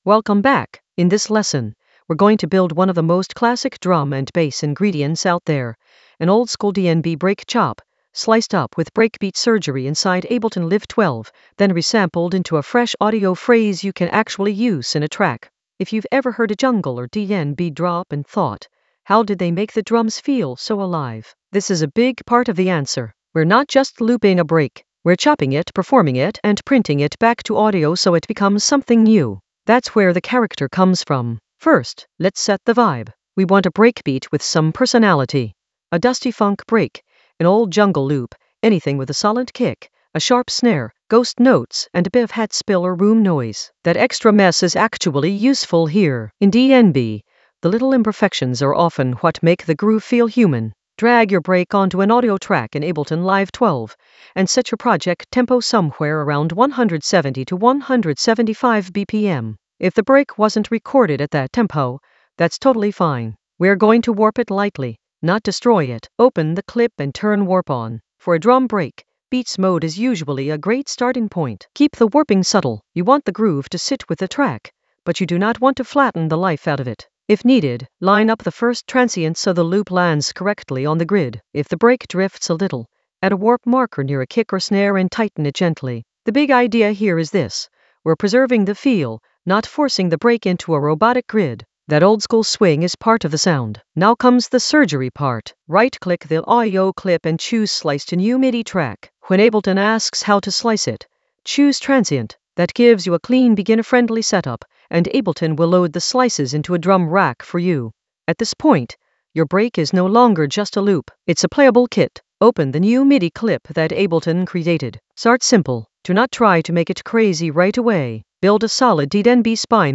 An AI-generated beginner Ableton lesson focused on Pull oldskool DnB chop with breakbeat surgery in Ableton Live 12 in the Resampling area of drum and bass production.
Narrated lesson audio
The voice track includes the tutorial plus extra teacher commentary.